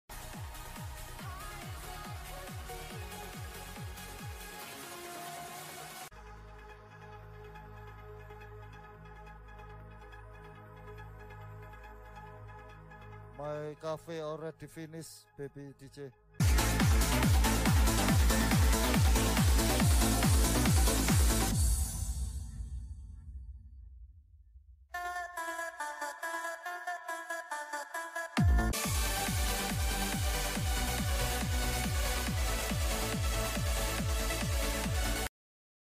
Trance Sets | 138 BPM